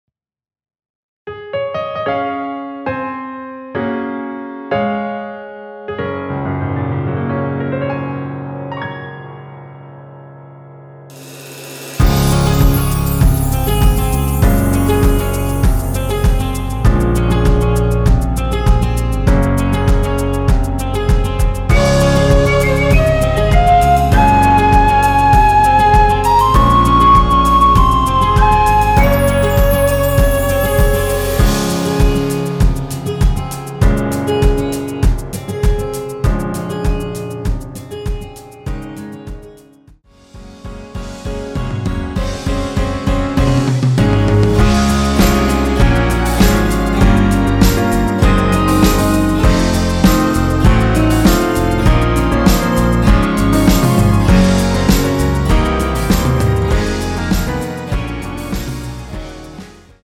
원키에서(-7)내린 MR입니다.
Db
앞부분30초, 뒷부분30초씩 편집해서 올려 드리고 있습니다.
중간에 음이 끈어지고 다시 나오는 이유는